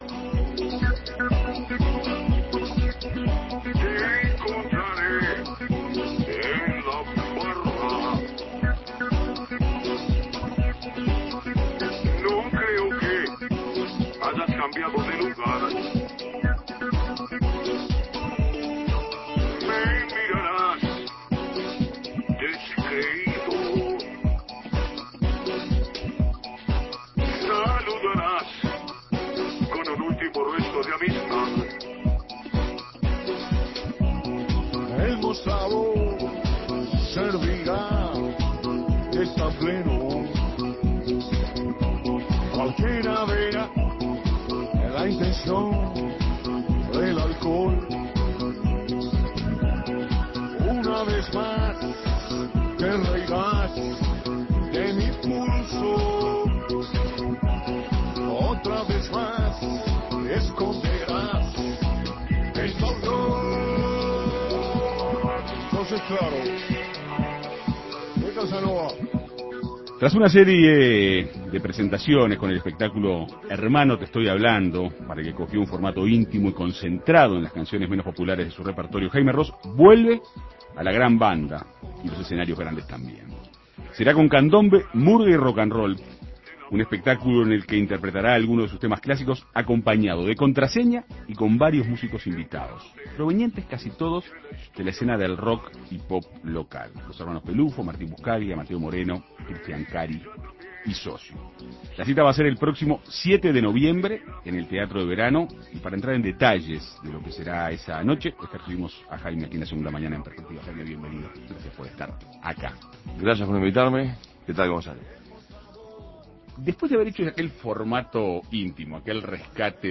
Se trata del espectáculo Candombe, murga y rocanrol, en el que interpretará, junto a artistas invitados del medio local, algunos de sus clásicos. En Perspectiva Segunda Mañana dialogó con el cantante.